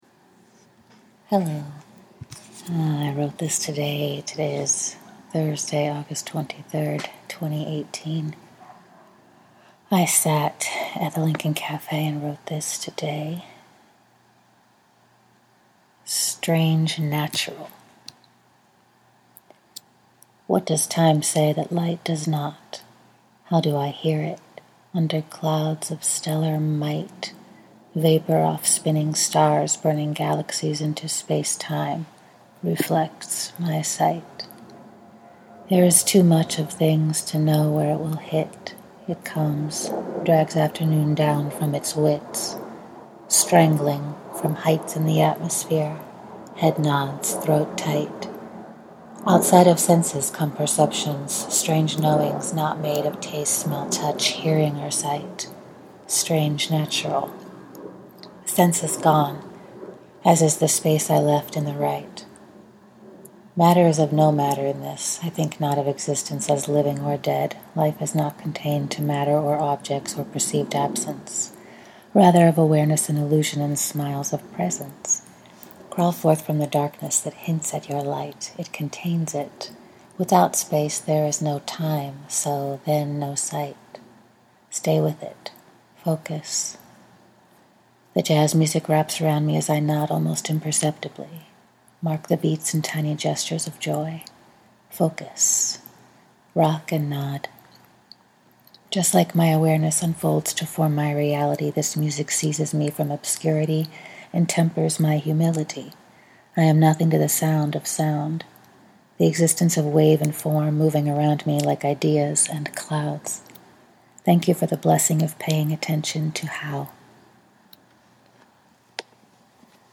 i read a poem i wrote today sitting at the cafe | August23 2018